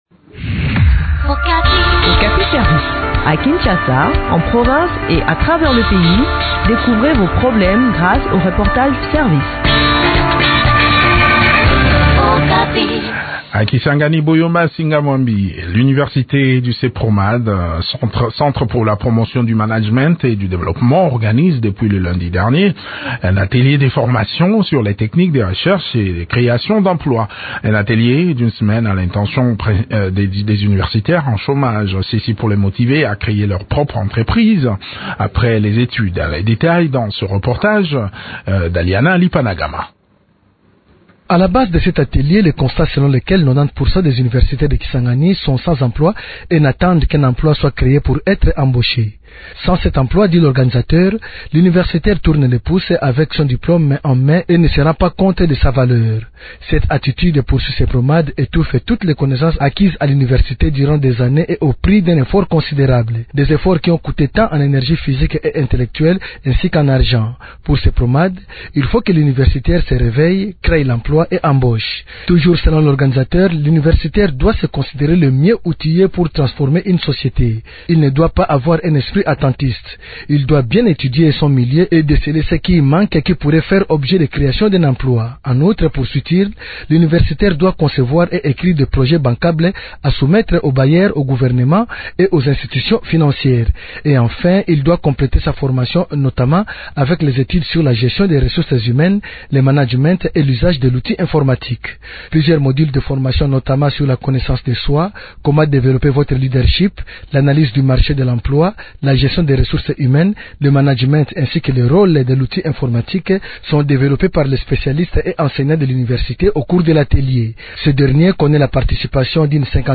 Le point sur le déroulement de ce séminaire atelier dans cet entretien